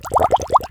SFX / Auras / Wet / Bubbles